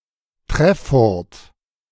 Treffurt (German pronunciation: [ˈtʁɛfʊʁt]